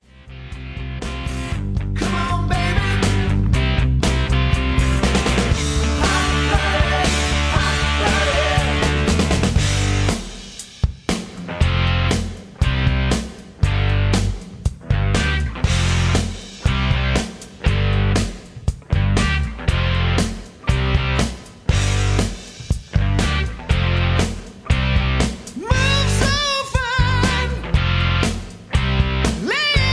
(Key-G) Karaoke MP3 Backing Tracks
Just Plain & Simply "GREAT MUSIC" (No Lyrics).